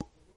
Button Click.mp3